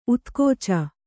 ♪ utkōca